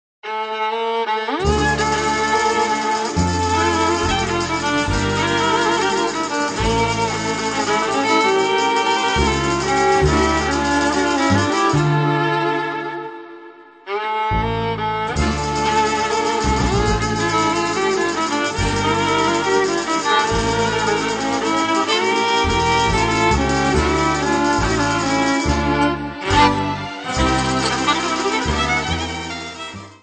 3 CD Set of Polish Gypsy Music.